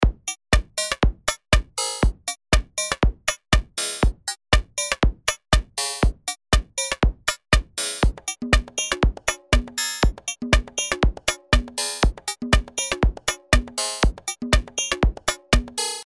Die Frequenzmodulation bietet über die Modulator-Parameter harsche bis tonal gefärbte Klänge. Hier habe ich zwischen beiden Varianten über einen externen Controller überblendet: